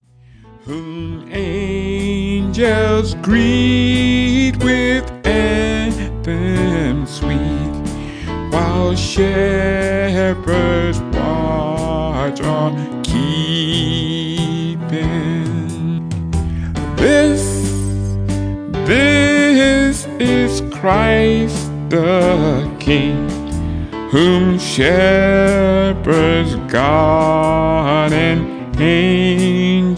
traditional Christmmas song